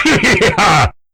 hehehehaw.wav